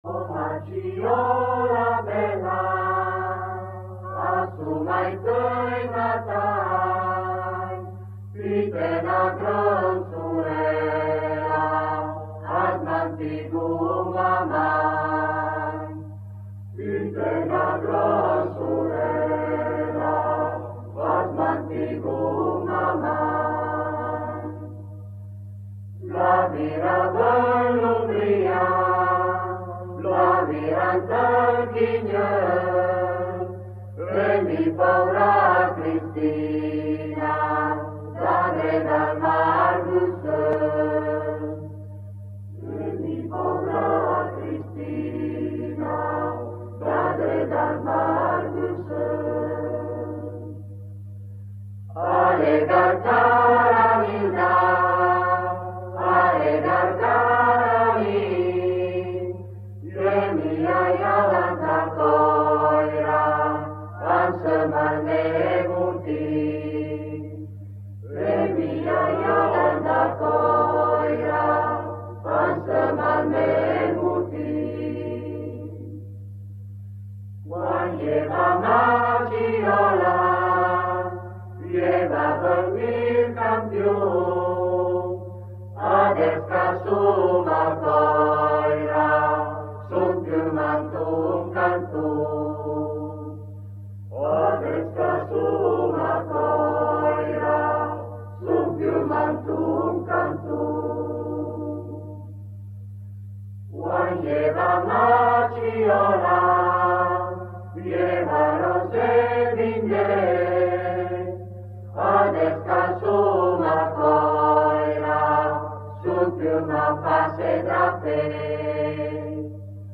Massiola
canto nuziale